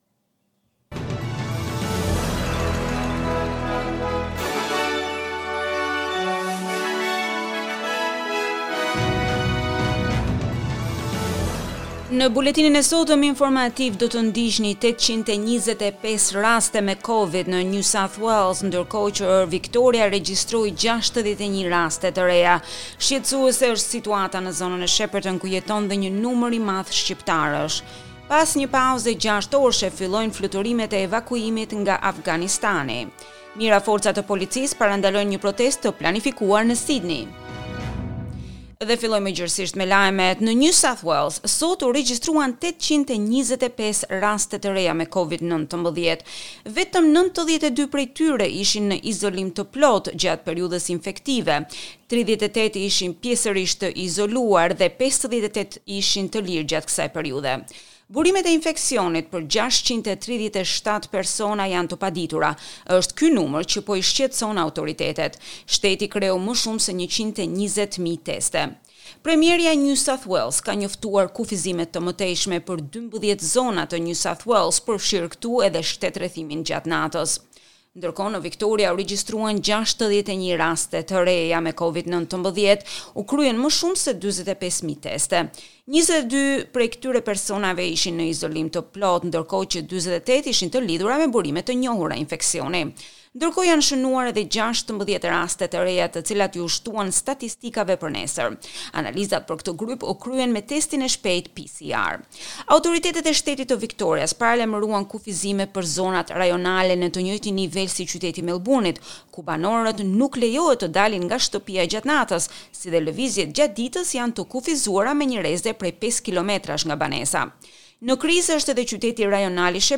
SBS News Bulletin in Albanian - 21 August 2021